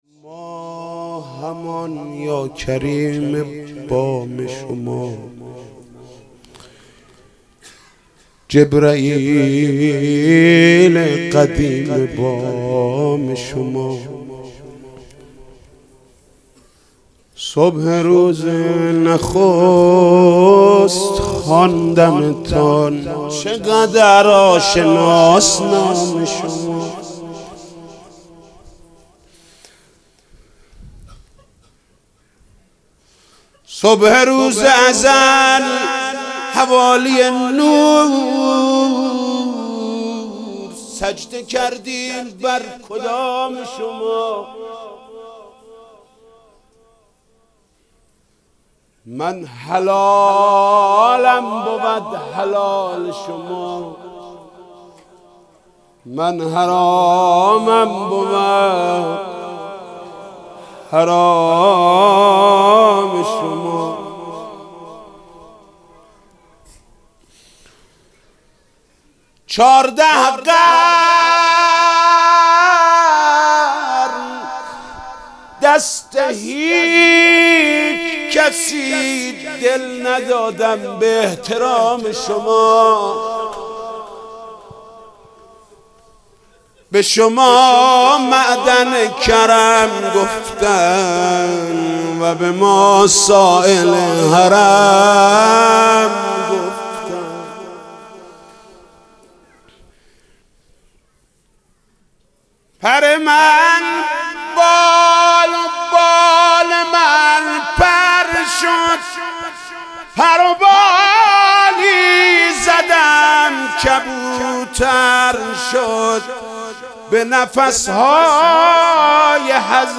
مدح: ما همان یا کریم بام شما